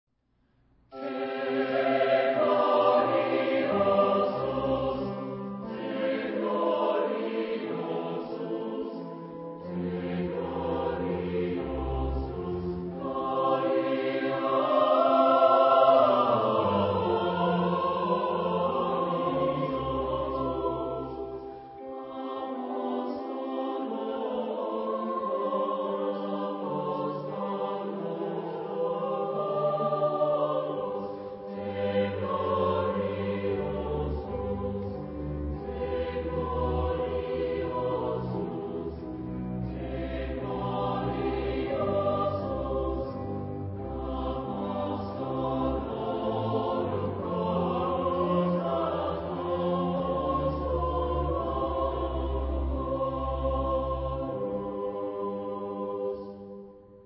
Epoque : 18ème s.
Genre-Style-Forme : Sacré ; Baroque
Type de choeur : SATB  (4 voix mixtes )